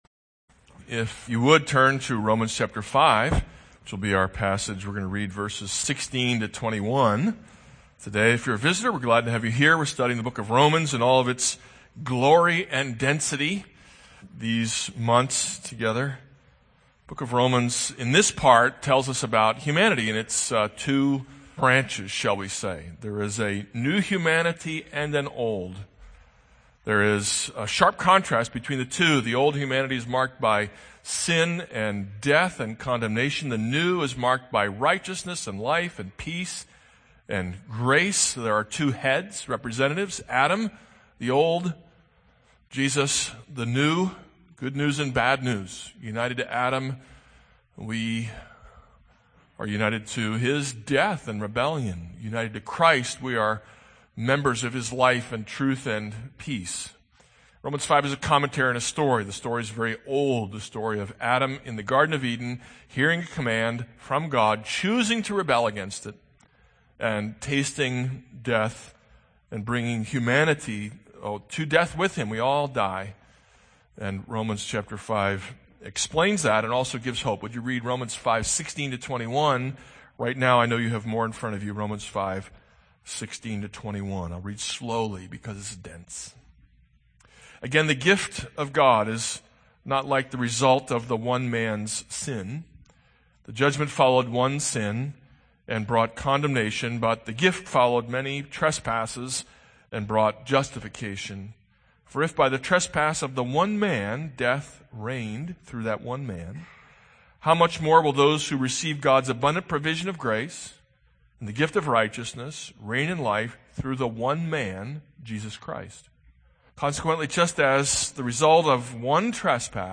This is a sermon on Romans 5:12-21 - hope for renewal.